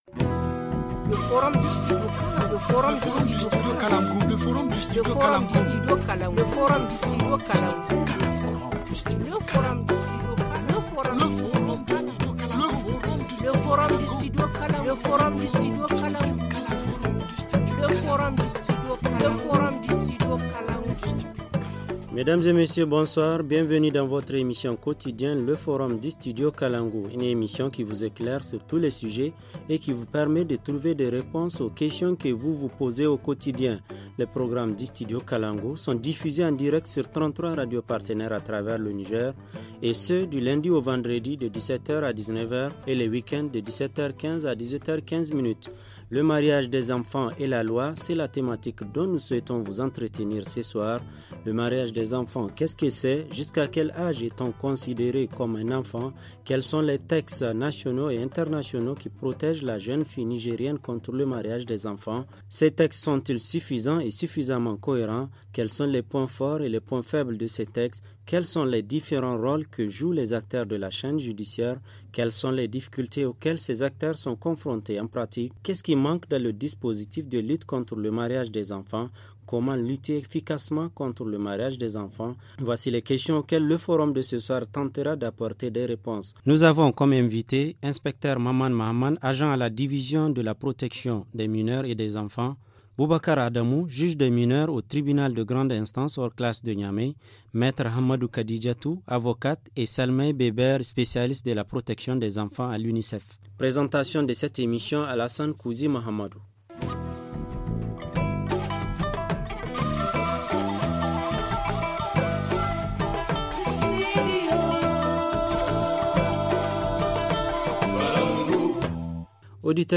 Le forum du 11/10/2018 - Le mariage des enfants au Niger: cadre juridique et réalités - Studio Kalangou - Au rythme du Niger